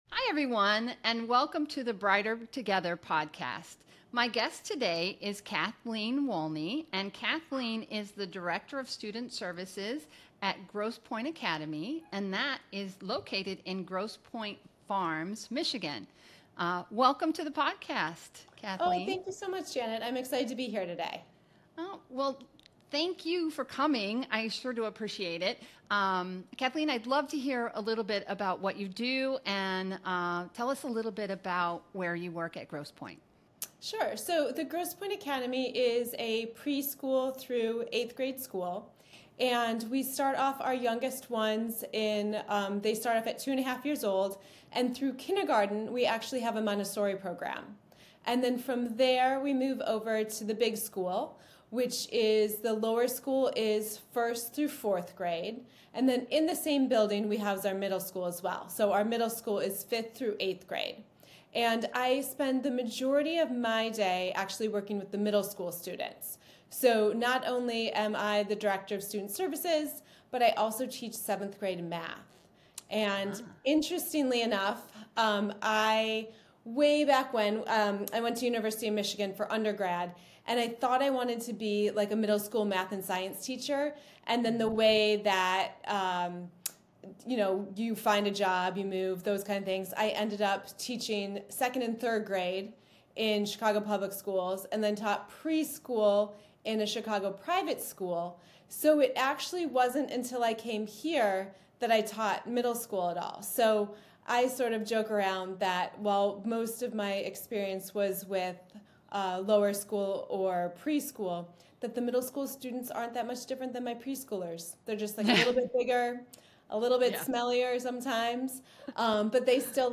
This conversation also explores the power of structured literacy and teacher training.